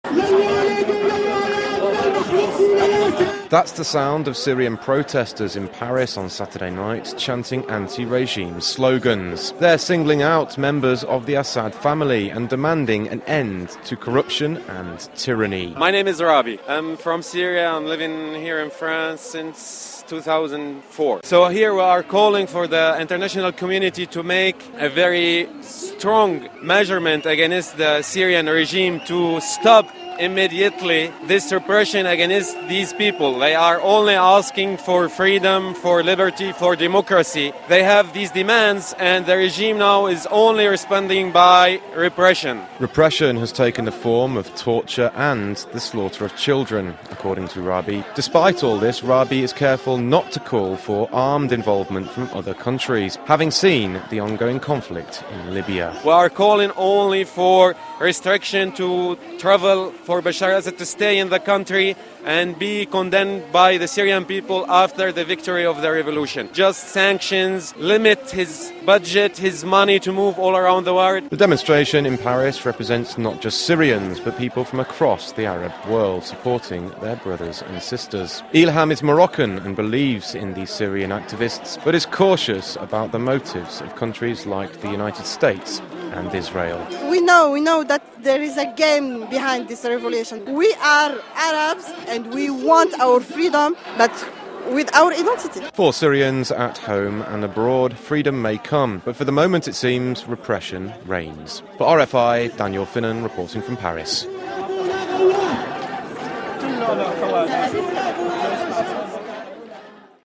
Radio Report: Anti-Assad protests in Paris